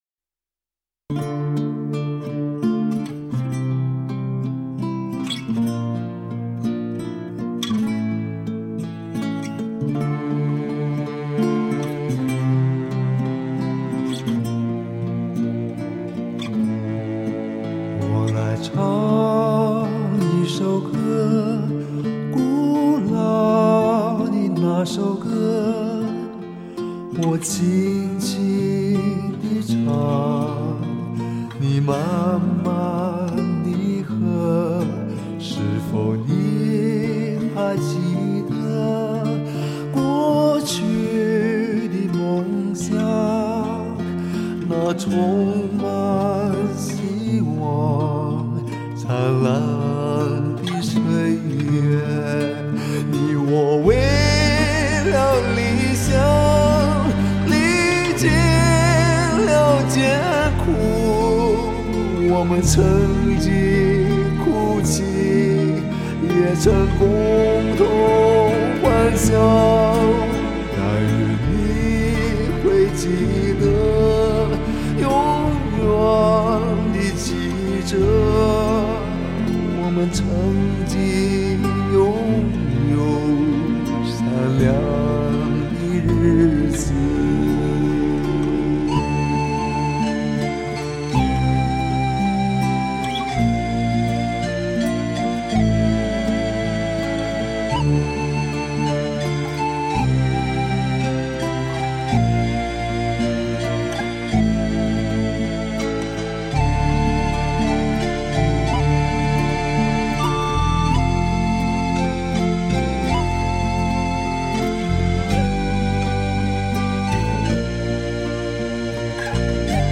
于是，原有的稚拙化成朴拙，明亮转向幽暗，少年的多愁变作深深的悲悼。